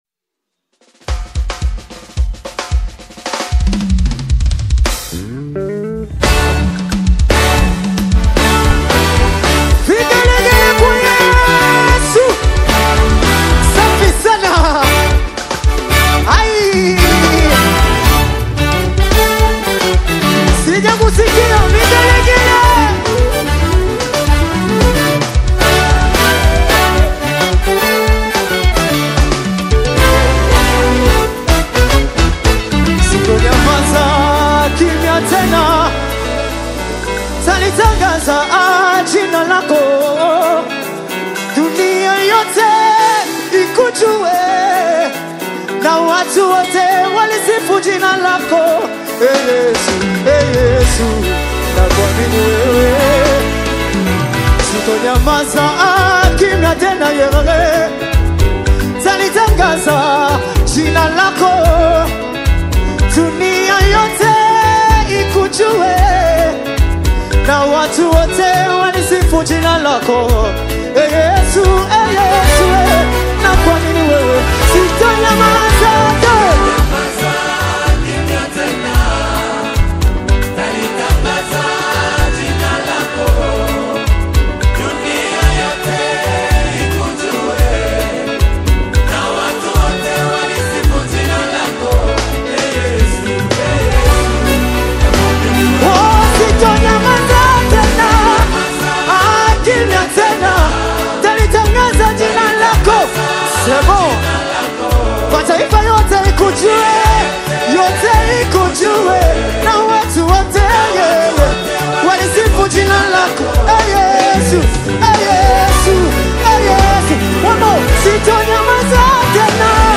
The instrumentally lush and vocally determined single